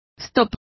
Also find out how stop is pronounced correctly.